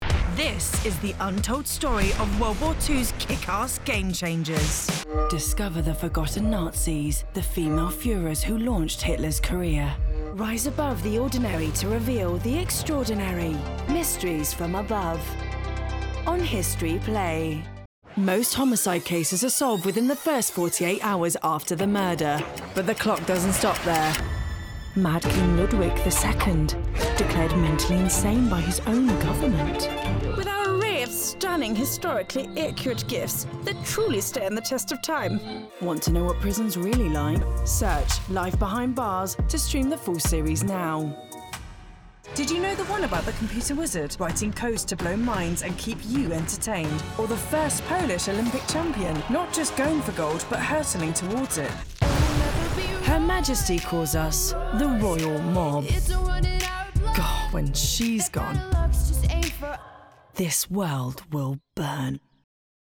Voice
Positive and clear